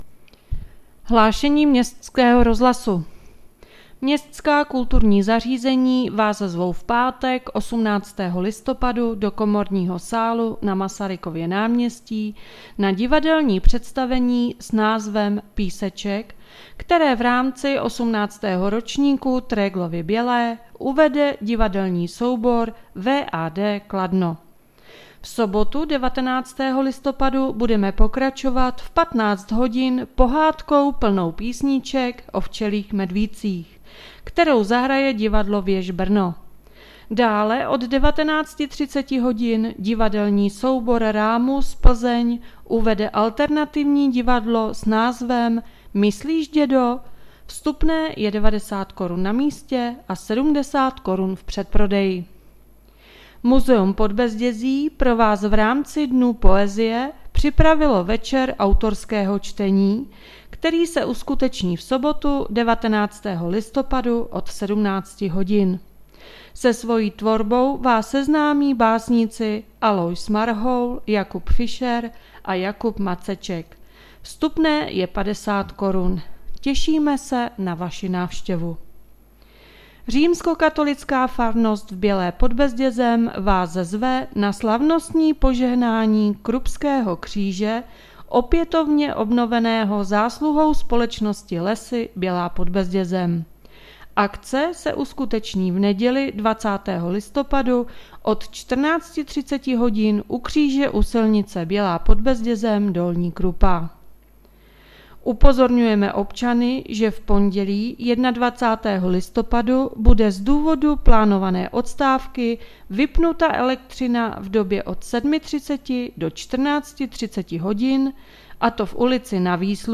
Hlášení městského rozhlasu 16.11.2022